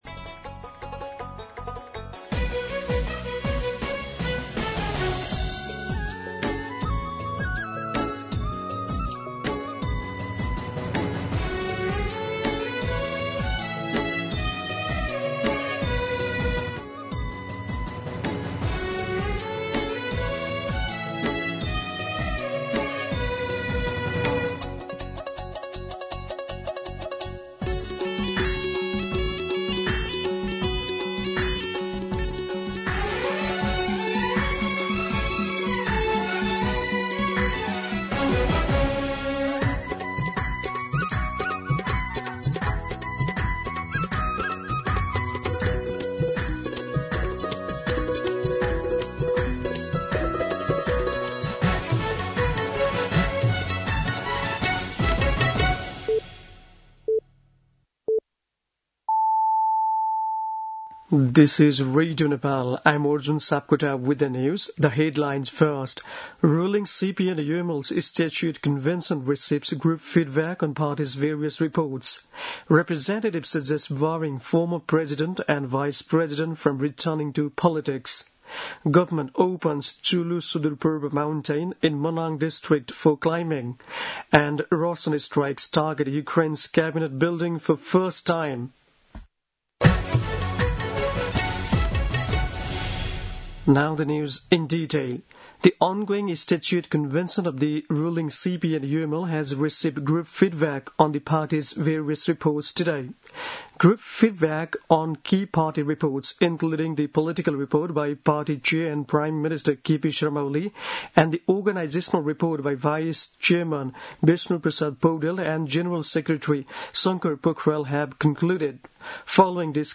दिउँसो २ बजेको अङ्ग्रेजी समाचार : २२ भदौ , २०८२